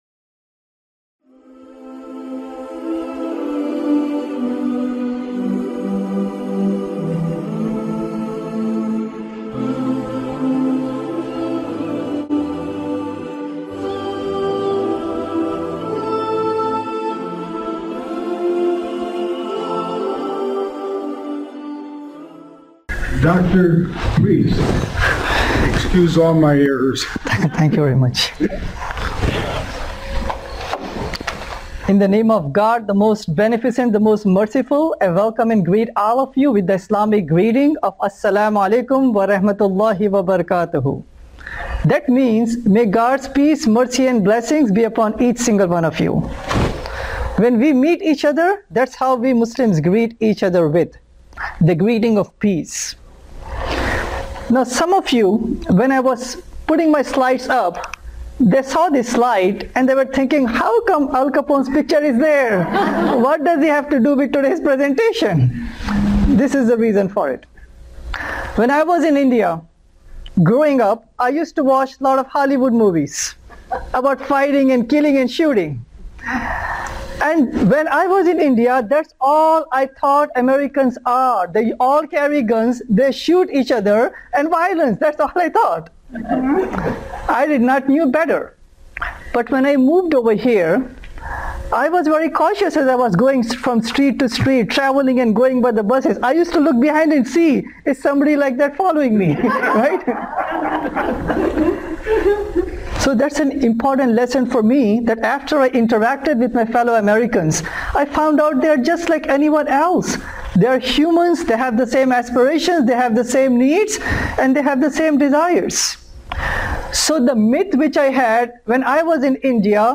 This educational and light-hearted approach helps bridge gaps and promote mutual respect among diverse communities.